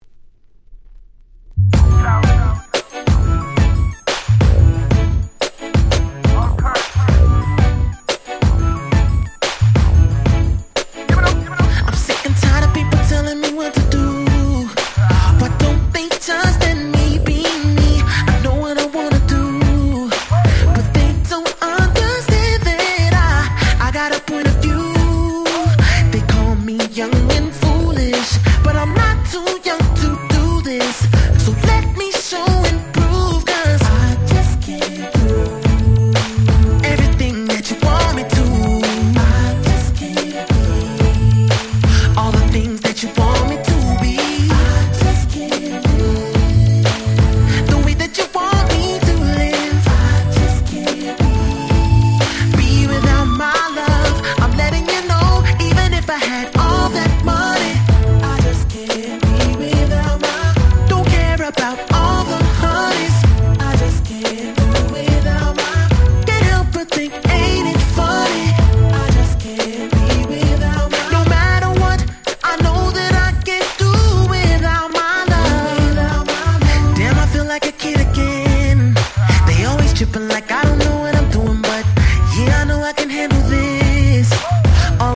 06' Nice UK R&B !!